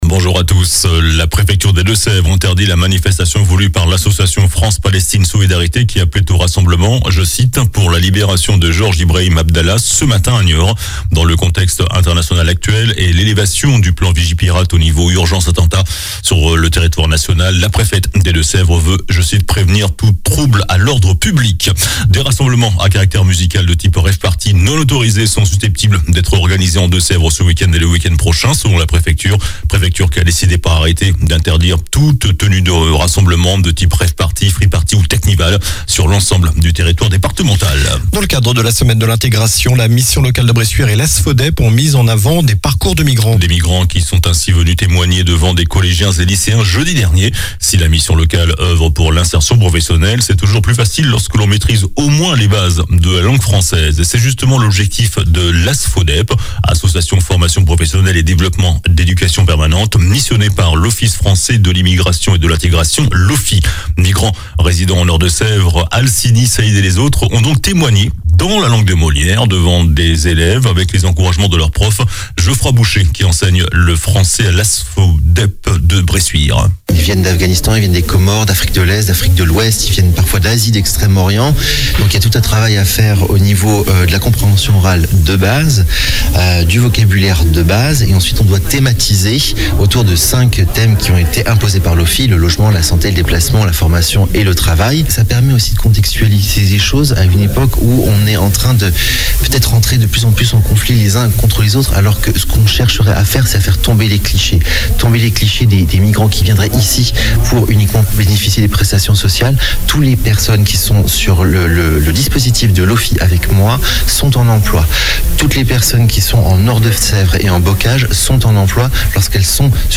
JOURNAL DU SAMEDI 21 OCTOBRE